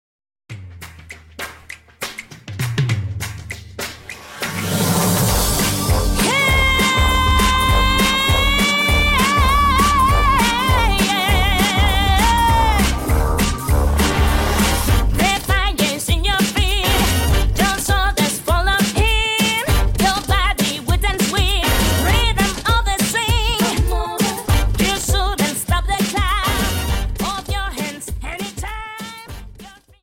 Dance: Quickstep 50 Song